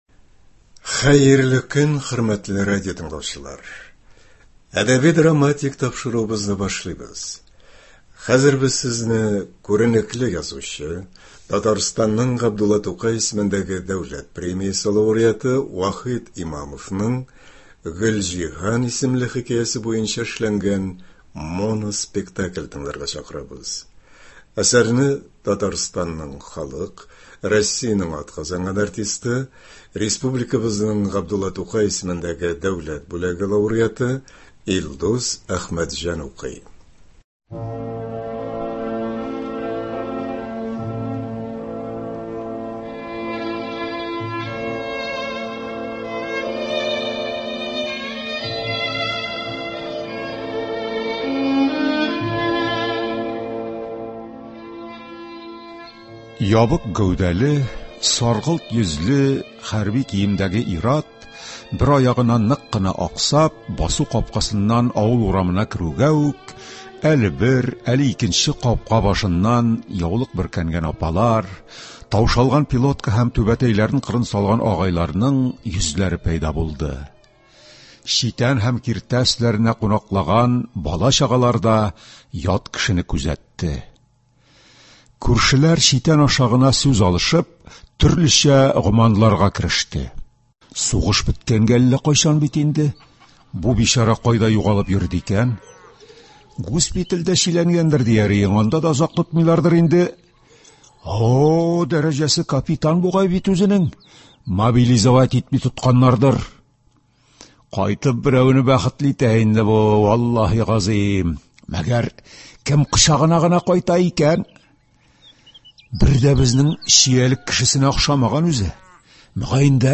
“Гөлҗиһан”. Моноспектакль премьерасы.